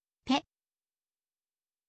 ออกเสียง: pe, เพะ